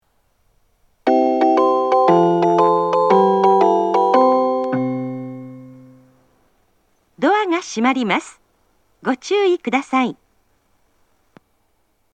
発車メロディー
一度扱えばフルコーラス鳴ります。
ユニペックス小型スピーカーは跨線橋側にあります。